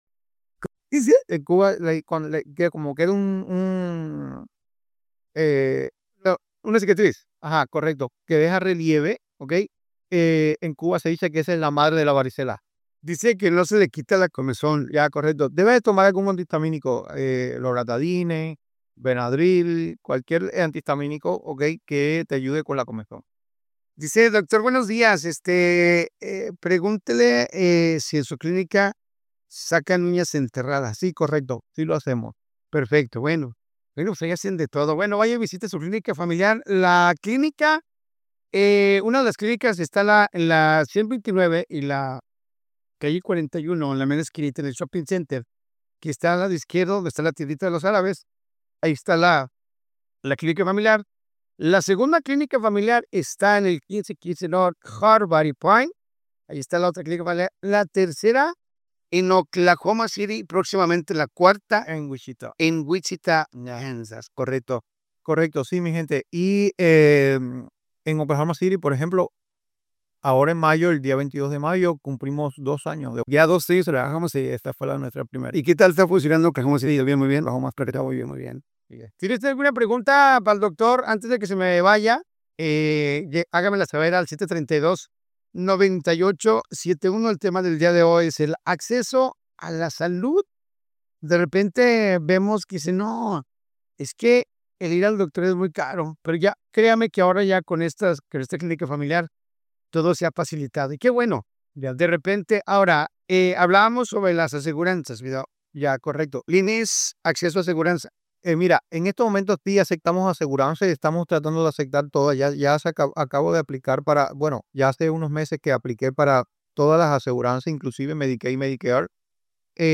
En una reciente entrevista, el cuerpo médico de la institución destacó que su objetivo es eliminar las barreras económicas y logísticas que impiden a las familias recibir atención médica de calidad.